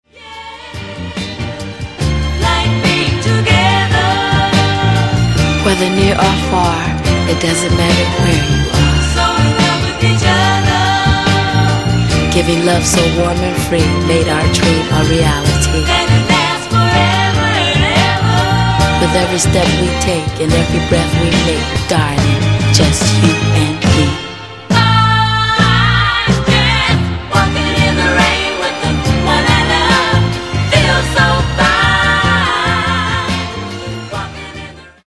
Genere:   Disco | Soul